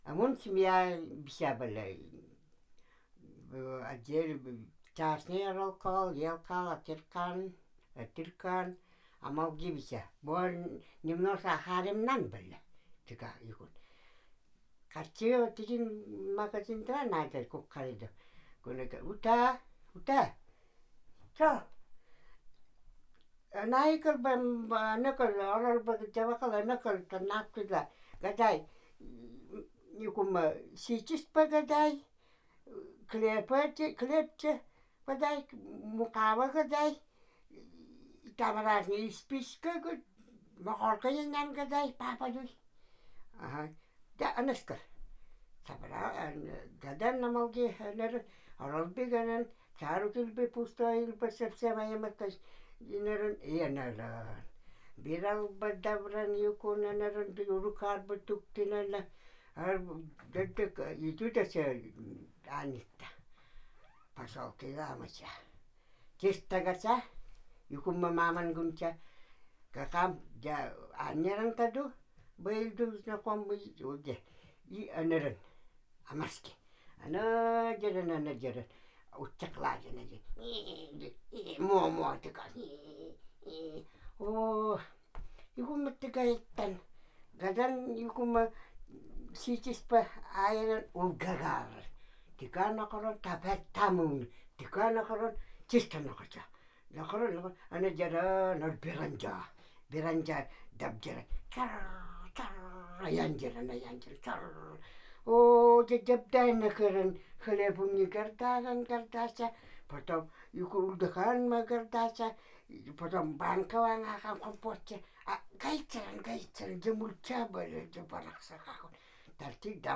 бытовые сказки
2021 — Экспедиция в эвенкам Аяно-Майского района Хабаровского края